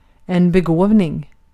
Ääntäminen
US : IPA : [ˈɡɪft]